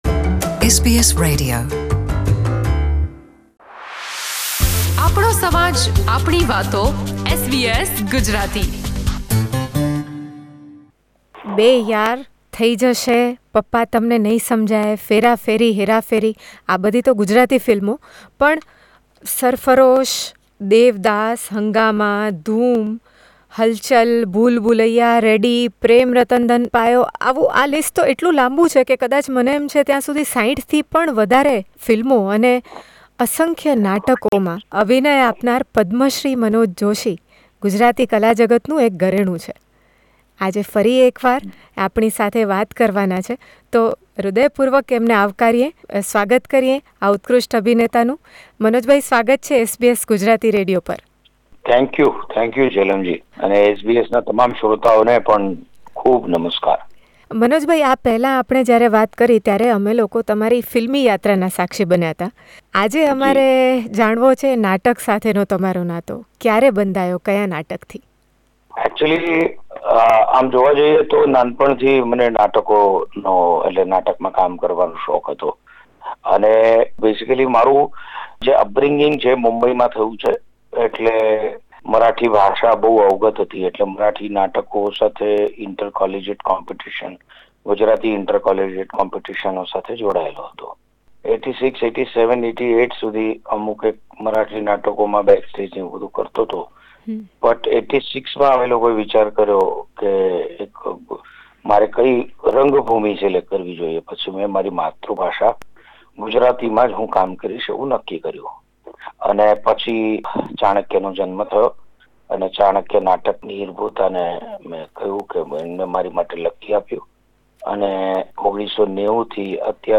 ગુજરાતી, મરાઠી ને હિન્દી એમ ત્રણ ભાષા અને નાટક, સિનેમા અને સિરિયલ એમ ત્રણ માધ્યમોમાં કામ કરનાર મનોજ જોષીએ ઈ.સ.1986માં રંગભૂમિ પર પસંદગી ઉતારી હતી. બાળપણથી જ નાટકનો શોખ ધરાવતા આ પદ્મ શ્રી કલાકાર SBS Gujarati સાથેની વાતચીતના આ પહેલા ભાગમાં વહેંચે છે પોતાનાં અત્યંત લોકપ્રિય નાટક ચાણક્ય સુધીની સફર.